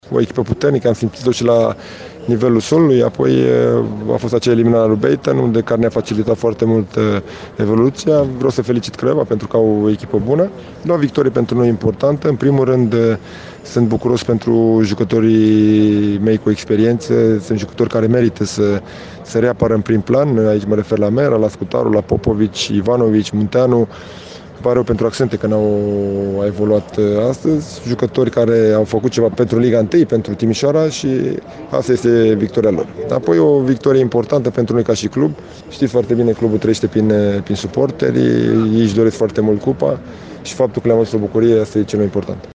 Antrenorul violeților, Dan Alexa, spune că echipa sa a întâlnit un adversar puternic: